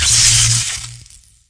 SFX电流音效下载
这是一个免费素材，欢迎下载；音效素材为电流音效， 格式为 wav，大小1 MB，源文件无水印干扰，欢迎使用国外素材网。